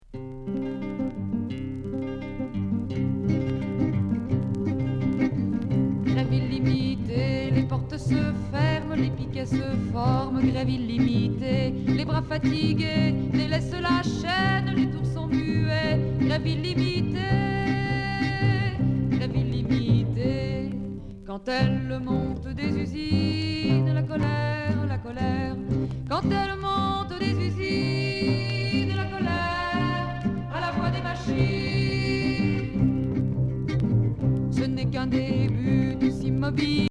Chanson folk révolutionnaire